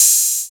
45 OP HAT.wav